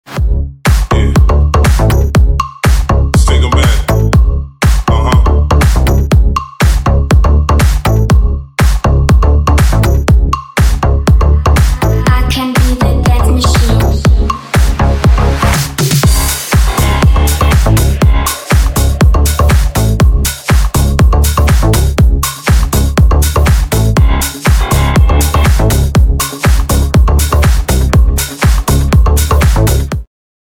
club
Стиль: Nu Disco, Indie Dance